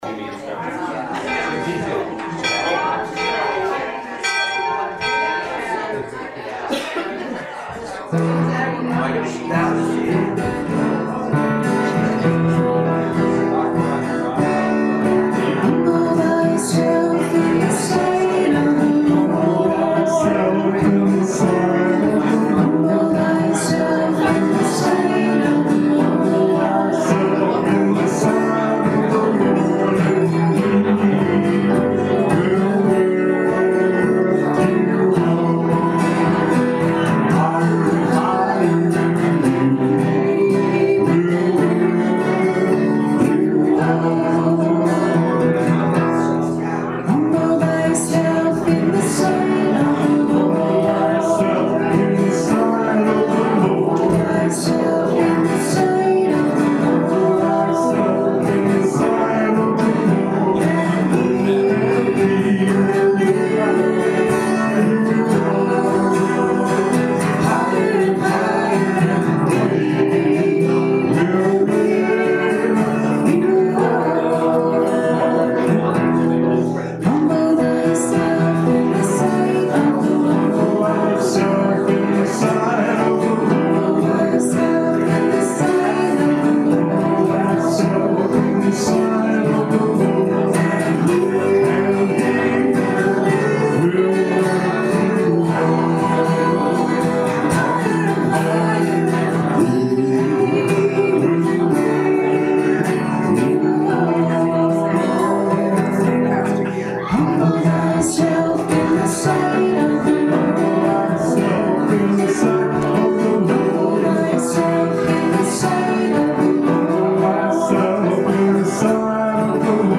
May 15th, 2016 Service Podcast
Welcome to the May 15th, 2016 Service Podcast.